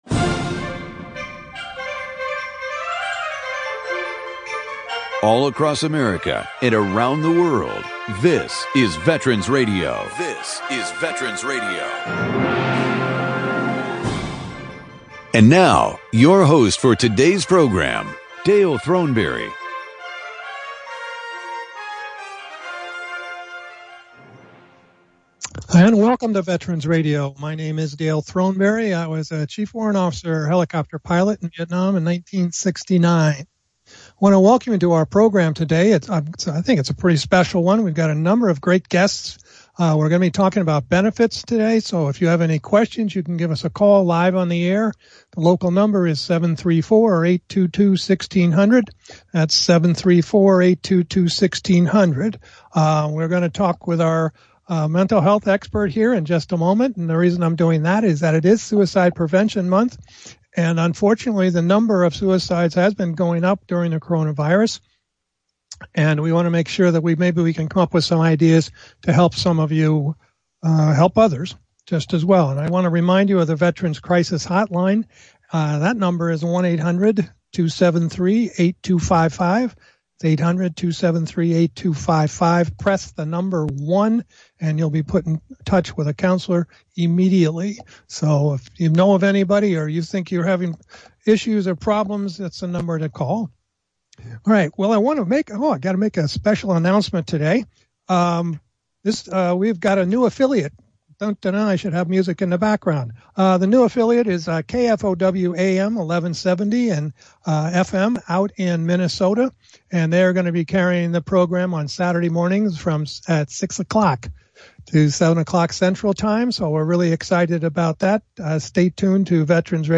Call in during the program to ask your question or offer a comment.